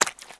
STEPS Swamp, Walk 25.wav